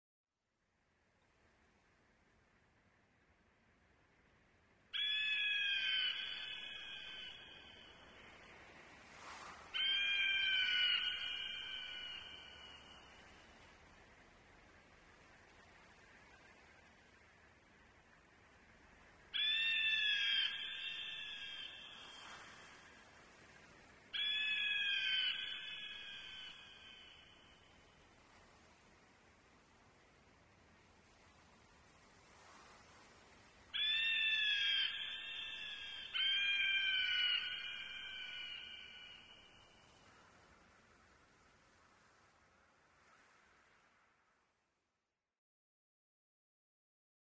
Звуки чайки
Чайка зовет вдали